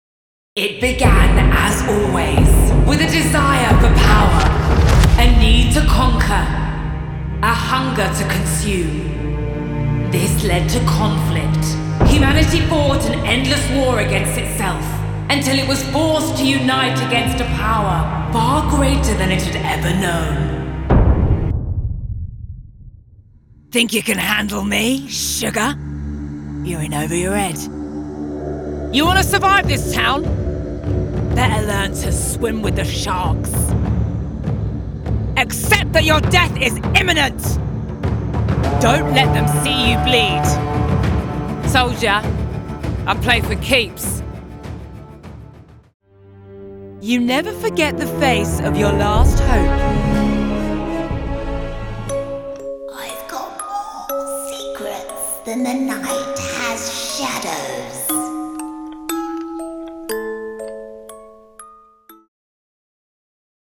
Neutral London.
• Female
• London
Gaming Reel